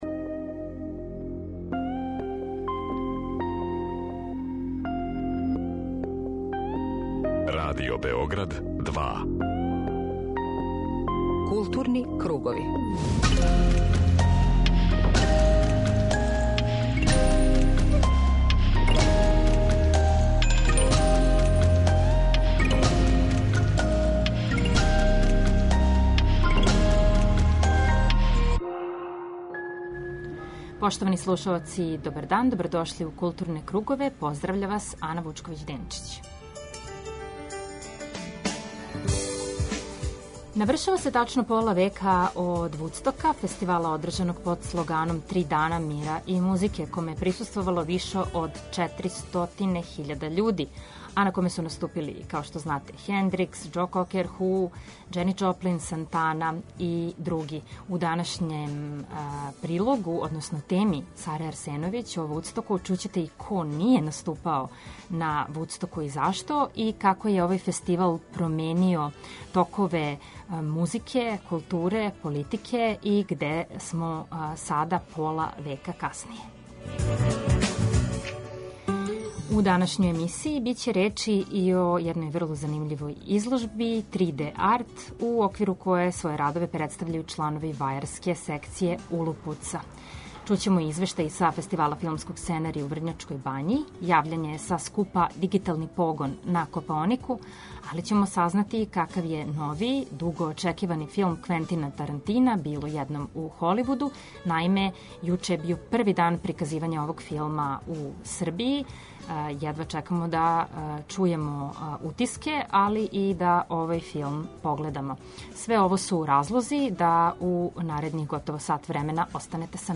Магазин културе Радио Београда 2